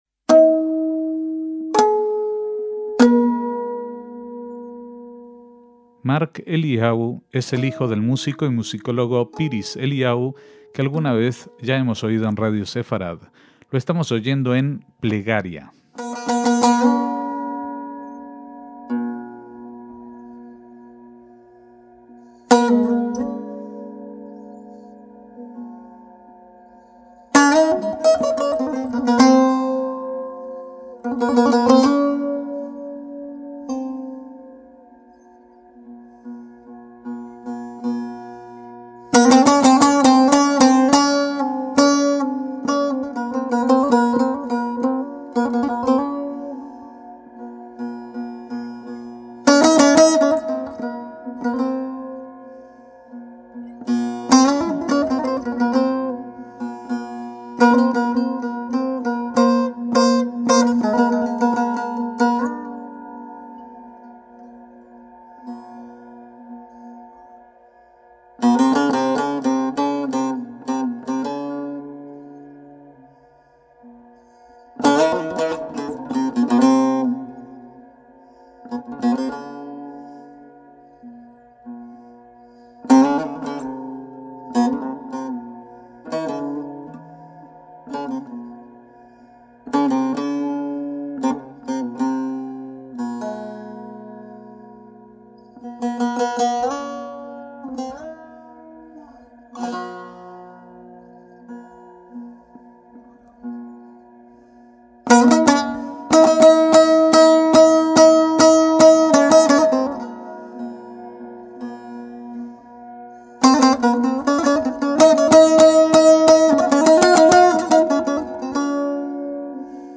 violín kemanché